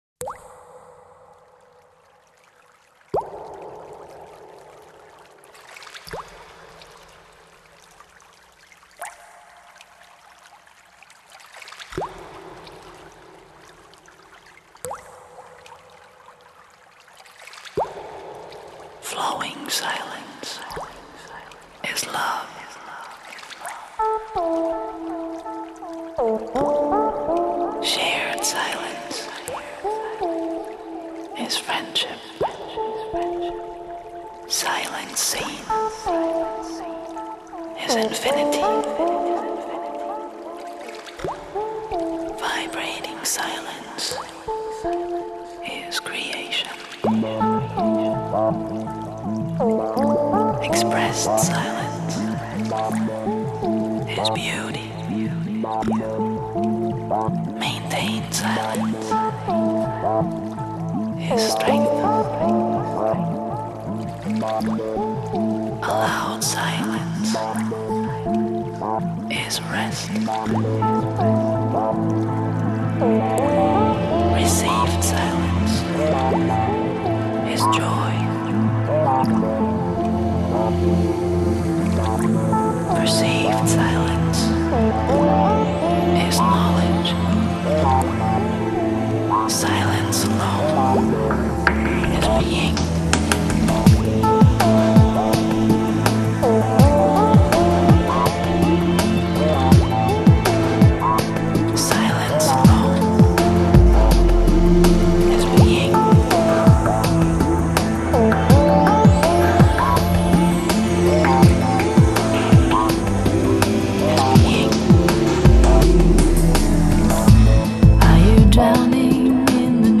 音乐流派：Electronic